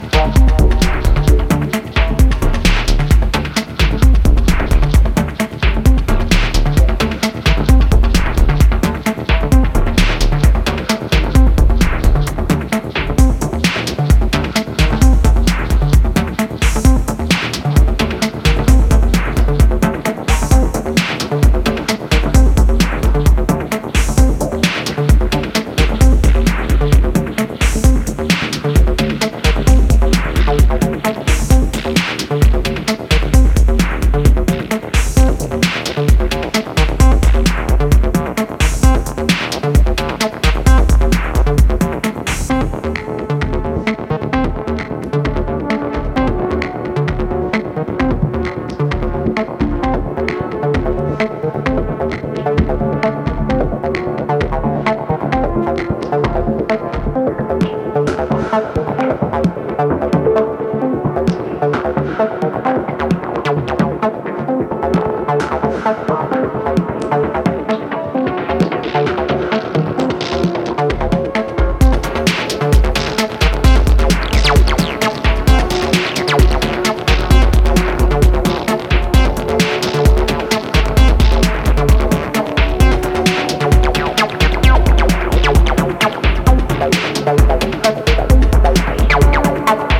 軽快なアシッドファンク131BPM